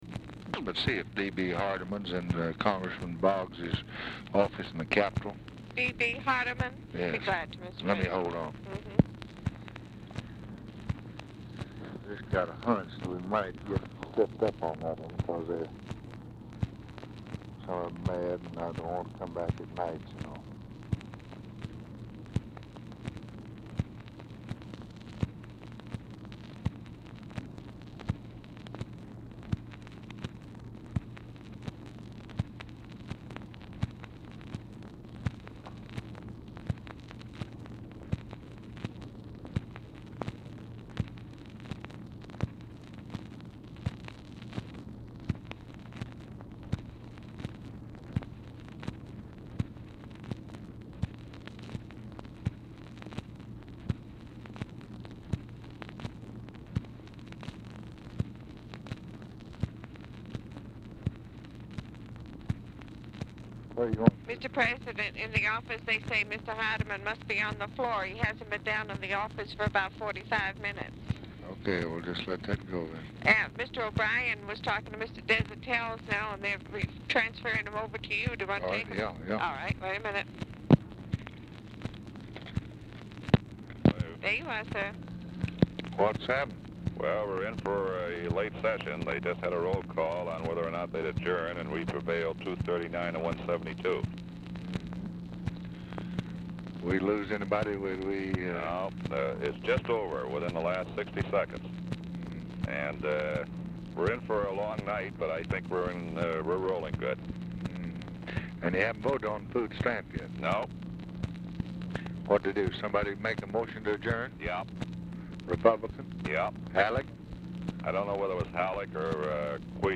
Telephone conversation # 2928, sound recording, LBJ and LARRY O'BRIEN, 4/8/1964, 9:51PM
Format Dictation belt
Location Of Speaker 1 Oval Office or unknown location